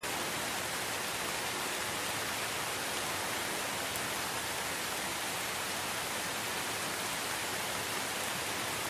rain.mp3